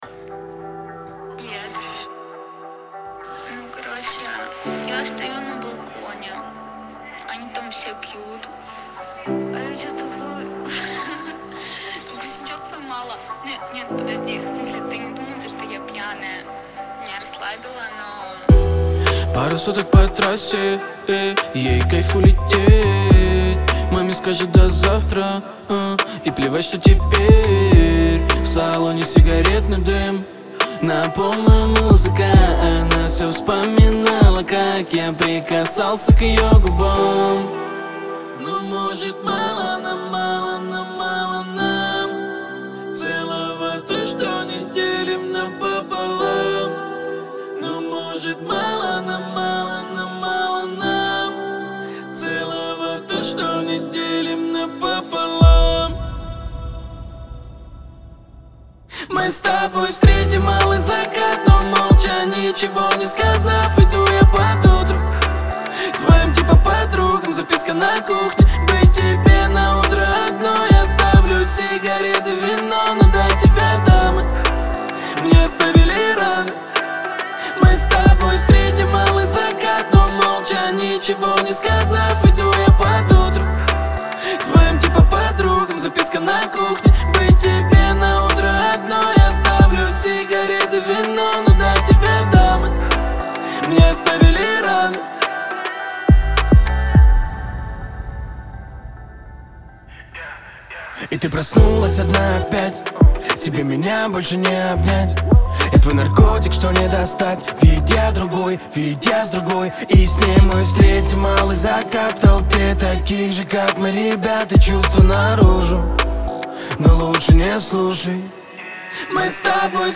это мощная и эмоциональная композиция в жанре хип-хоп